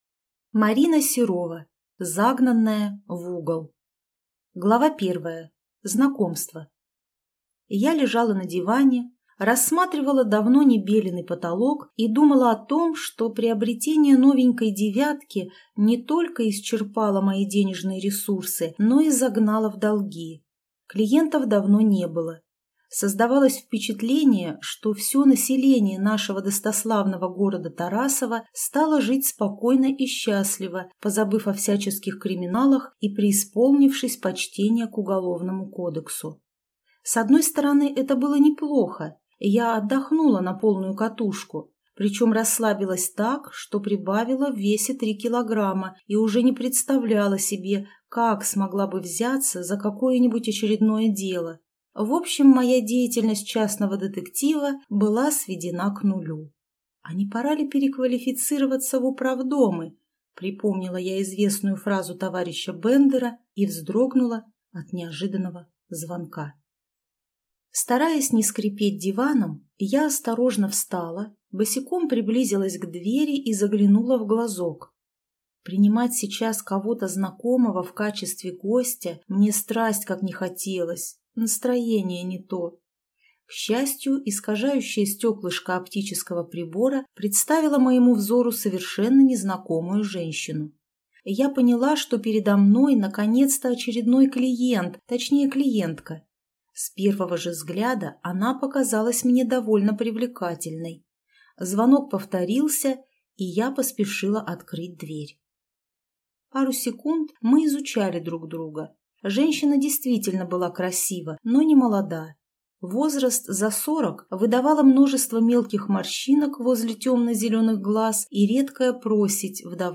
Аудиокнига Загнанная в угол | Библиотека аудиокниг